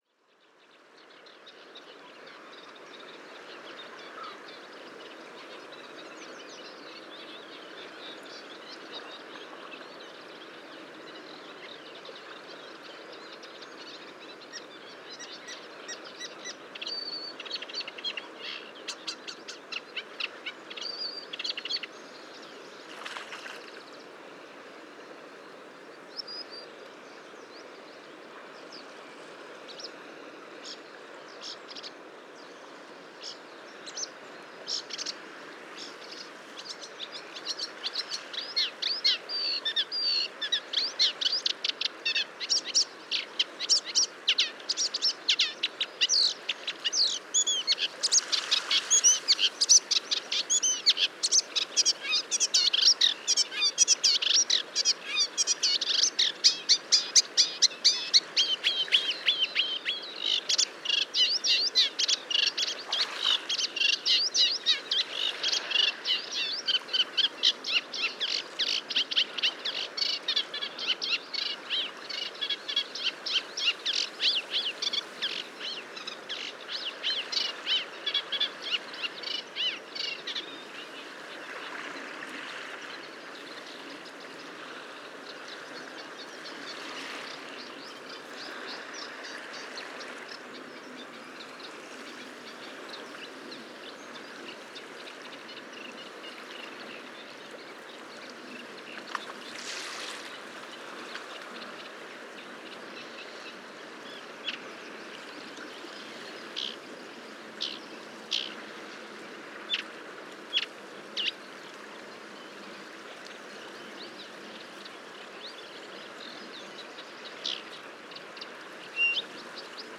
PFR10740, 1-00, 150504, Common Reed Warbler Acrocephalus scirpaceus, song, counter song
Zuidlaardermeer, Netherlands, Telinga prabolic reflector
PFR10748, 1-11, 150504, Common Tern Sterna hirundo, scolding calls
Zuidlaardermeer, Netherlands, Telinga parabolic reflector